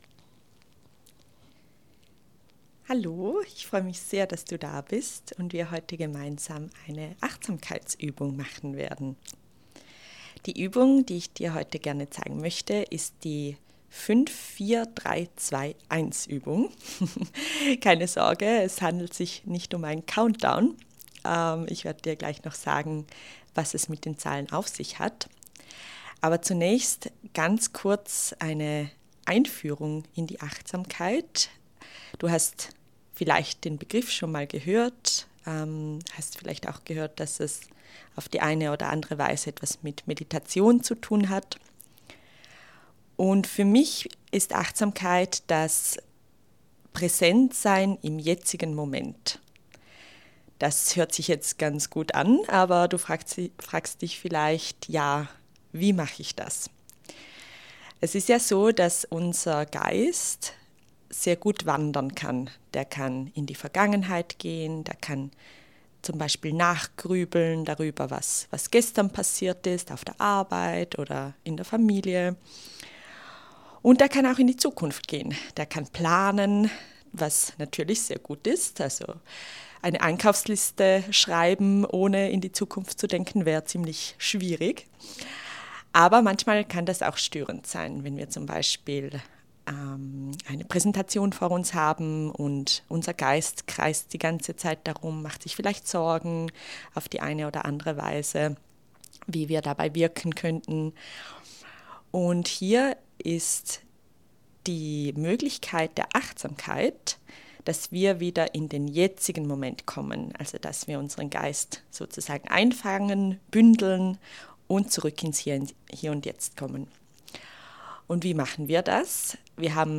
Deine kostenlose Audio-Achtsamkeitsübung